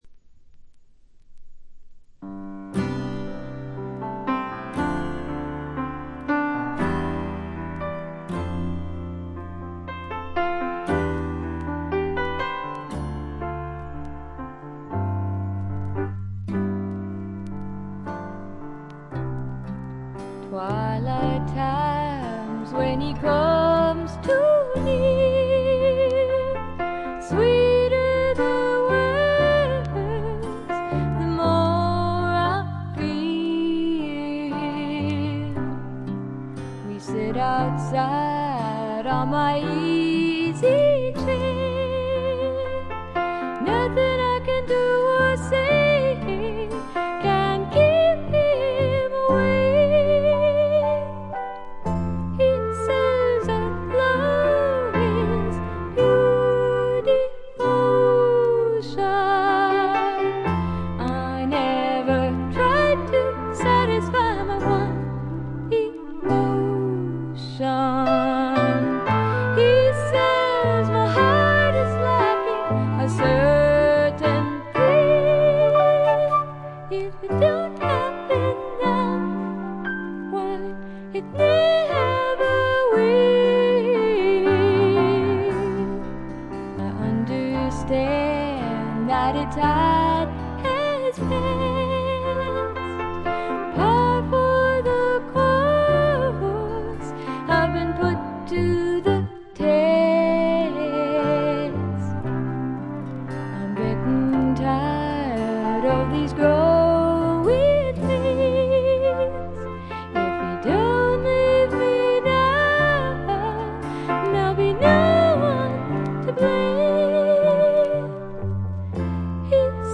これ以外はところどころでチリプチ。
試聴曲は現品からの取り込み音源です。